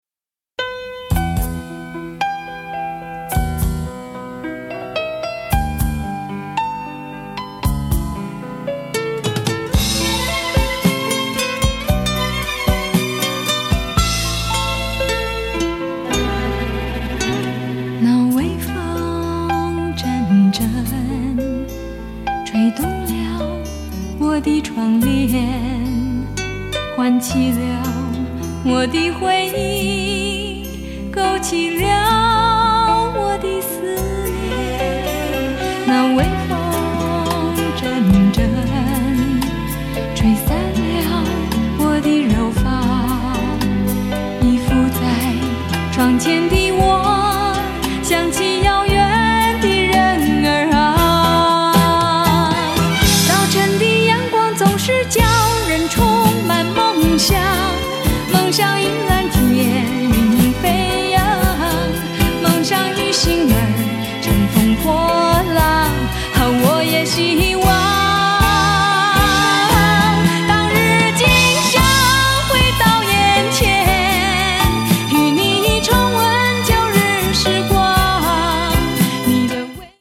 ★ 虛無縹緲的天籟嗓音，蘊涵夢幻般的極致柔美！
★ 細膩幽邃的優雅歌聲，瀰漫氤氳般浪漫的韻味！